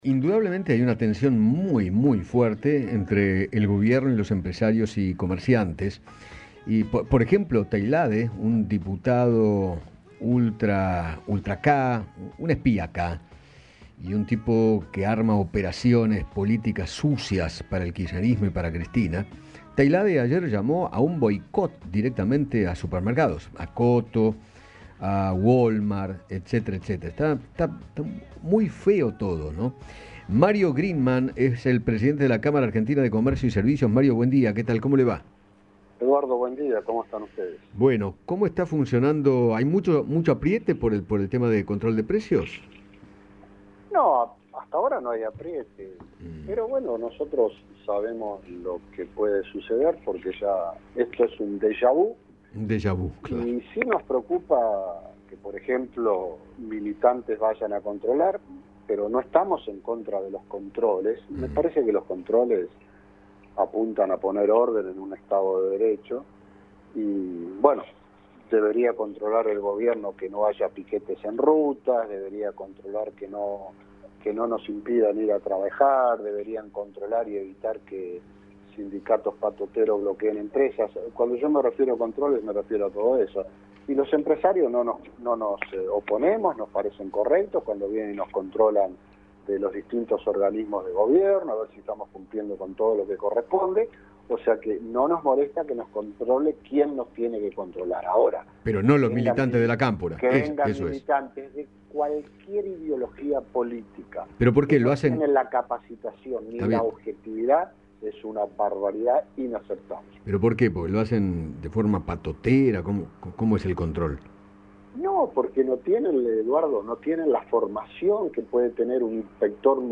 habló con Eduardo Feinmann sobre el control de precios por parte de militantes y aseguró que no están en contra de la medida, sino de quiénes vigilarían.